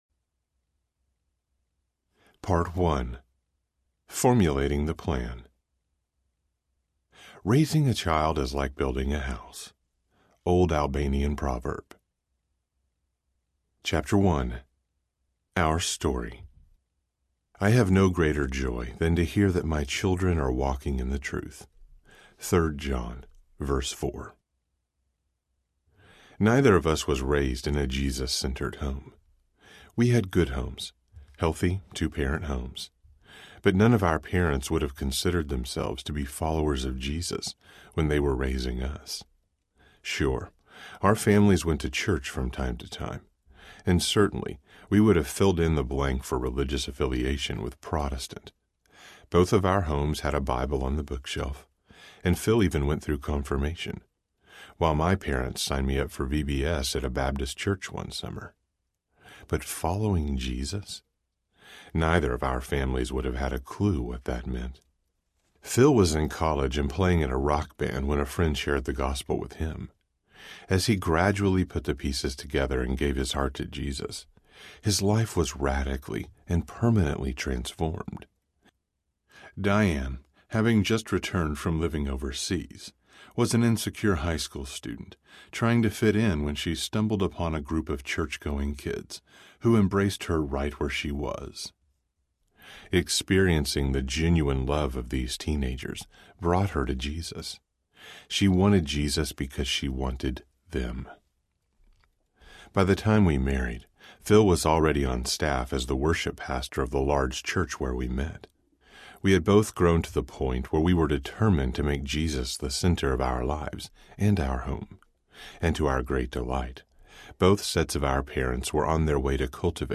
Raising Passionate Jesus Followers Audiobook